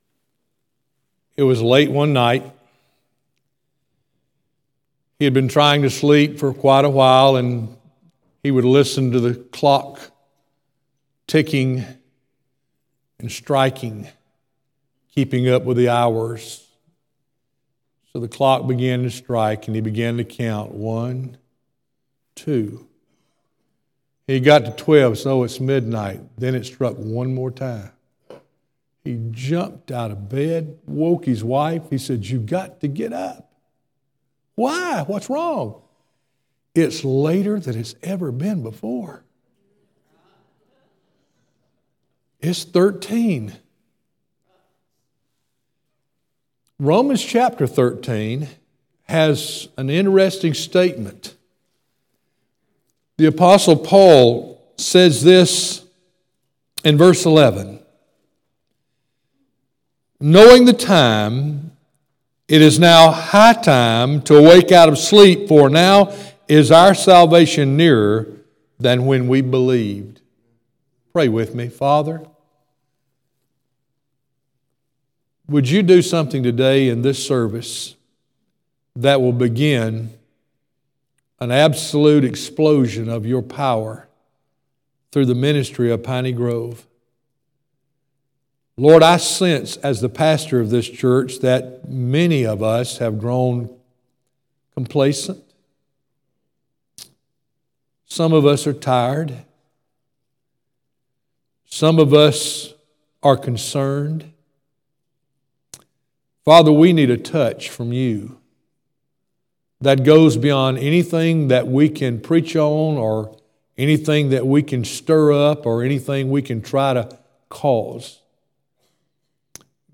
Piney Grove Baptist Church Sermons